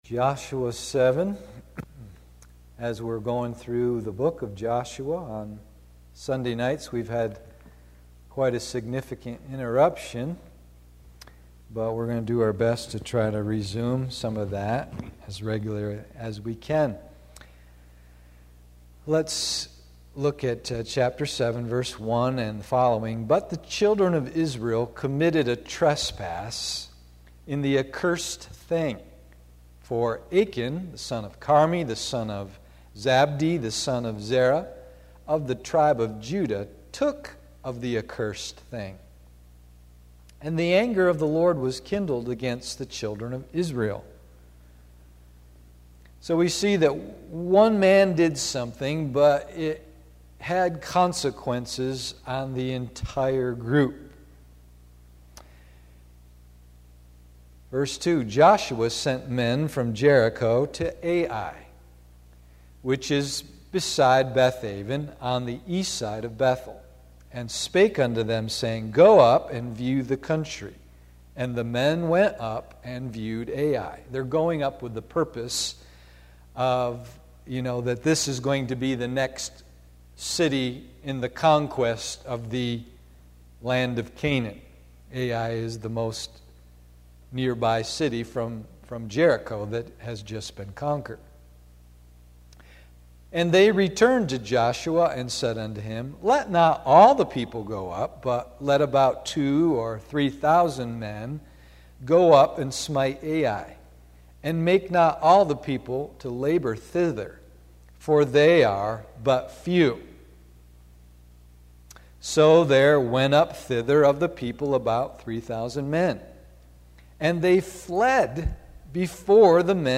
Joshua 6-7 & Pilgrim’s Progress Study PM Service